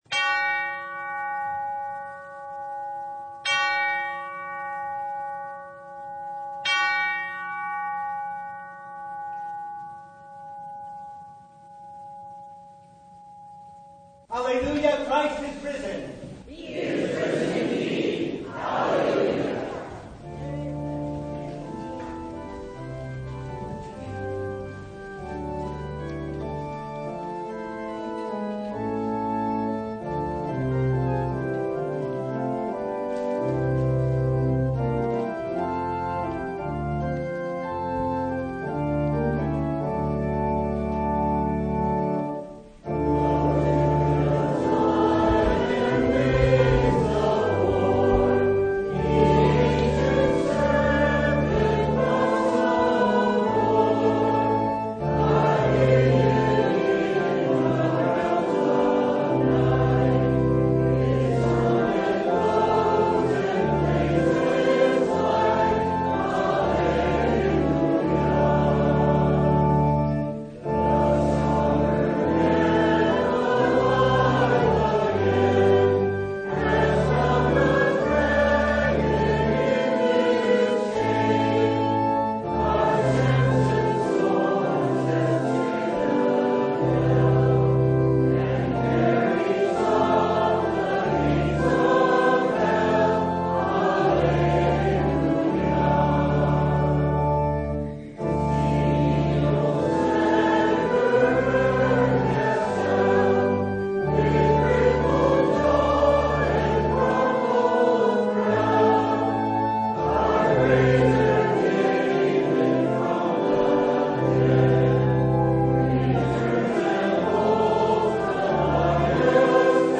Download Files Notes Bulletin Topics: Full Service « Jesus Is Risen!